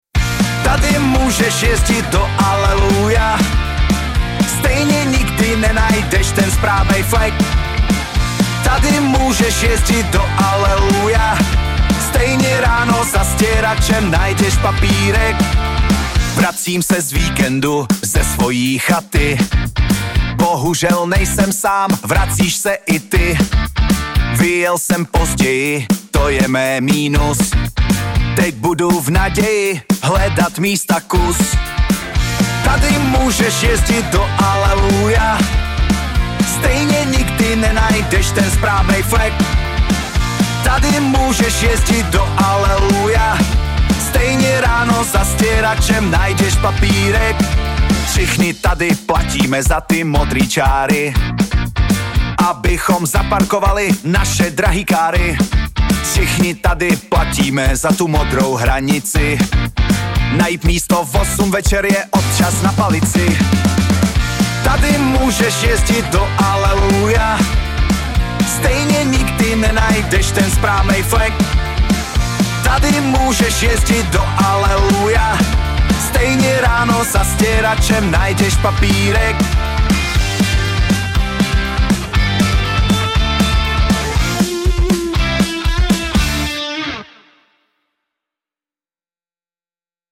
Generated track
Ready-to-play MP3 from ElevenLabs Music.